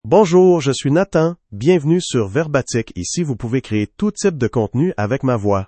NathanMale French AI voice
Nathan is a male AI voice for French (Canada).
Voice sample
Male
Nathan delivers clear pronunciation with authentic Canada French intonation, making your content sound professionally produced.